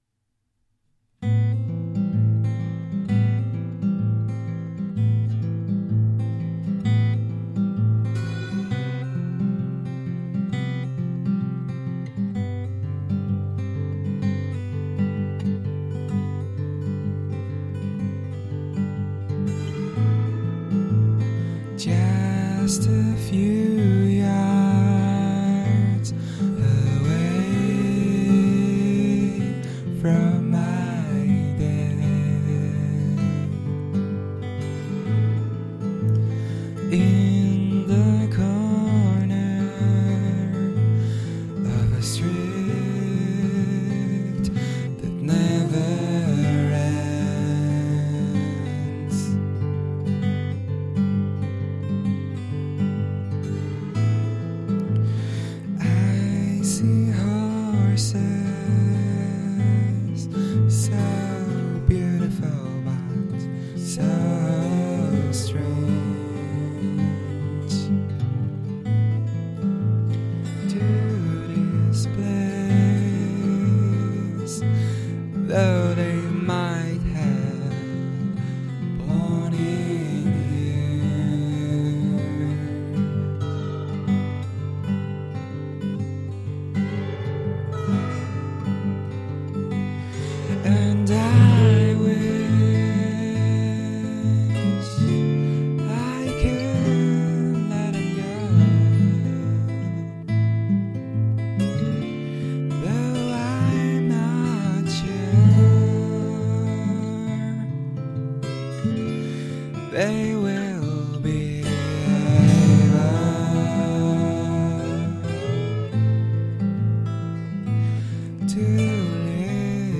note: this is a very raw, un-touched recording with bunch of mstakes.
i only amplified it (7.0+) because i sang pretty far from the mic to avoid the boomie sound.